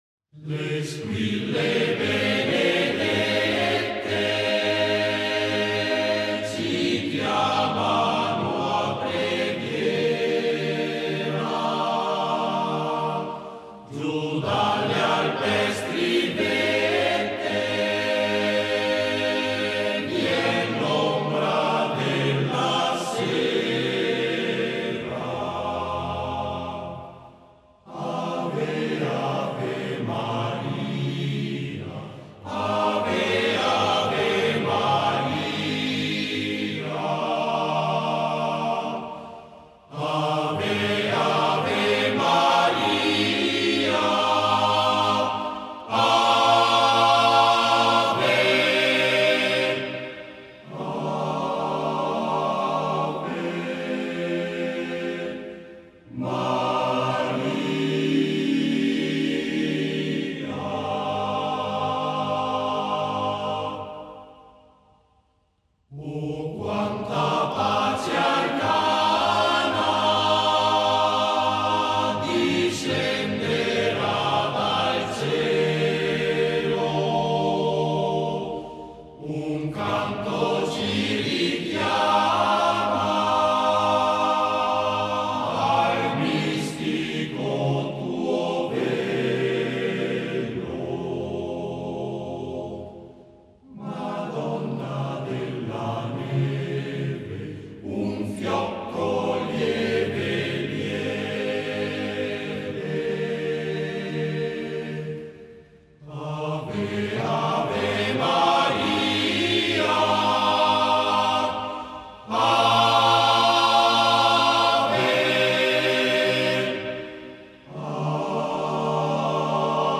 Esecutore: Coro SOSAT